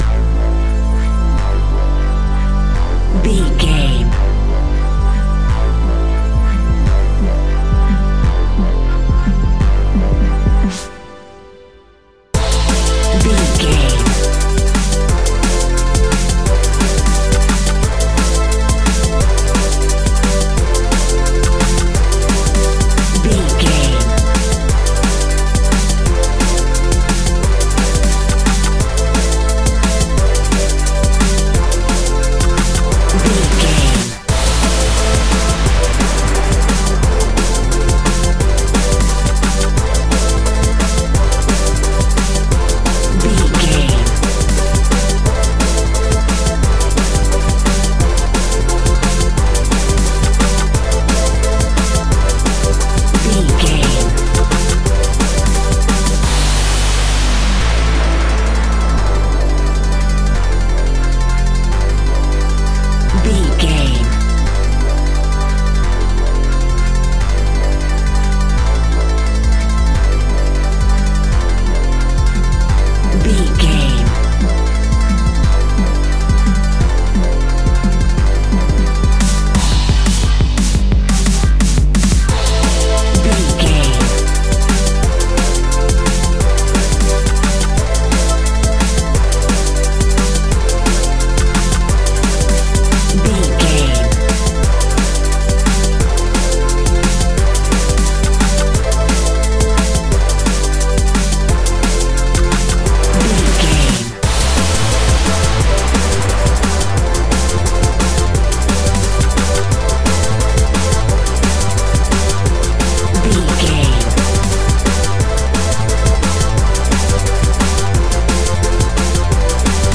Epic / Action
Fast paced
Aeolian/Minor
aggressive
dark
driving
energetic
frantic
futuristic
synthesiser
drum machine
electronic
sub bass
synth leads
synth bass